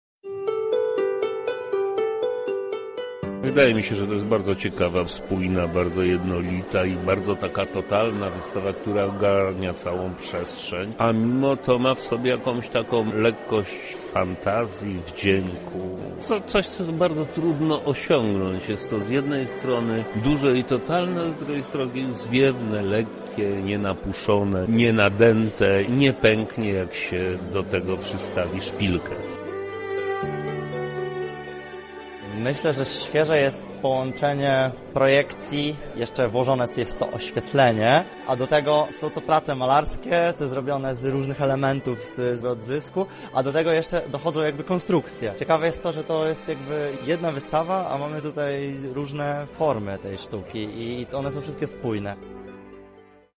Zapytaliśmy zebranych o wrażenia z wernisażu:
Sonda